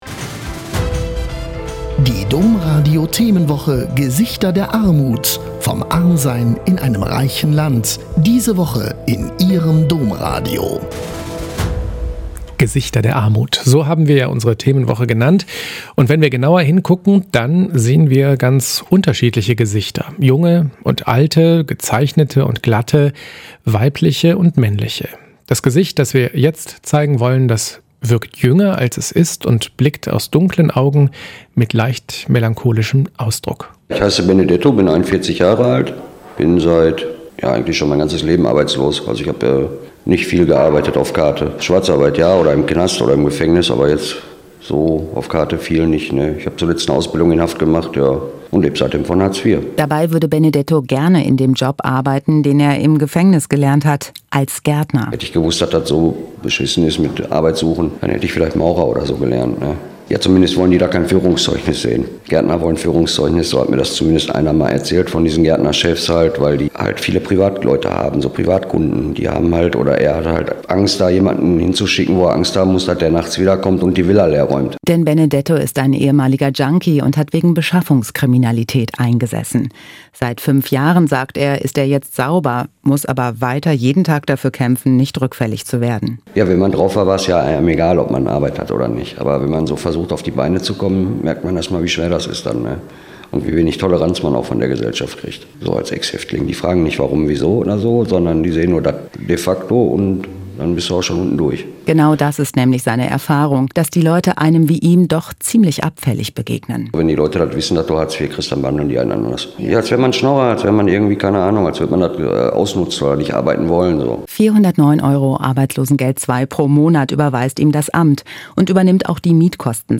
Interviewmitschnitte Domradio Köln (Nov.2017)